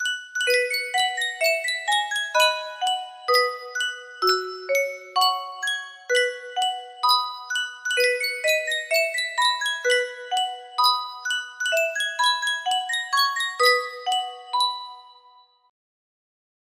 Sankyo Spieluhr - Meine Oma fährt im Hühnerstall Motorrad YRQ music box melody
Full range 60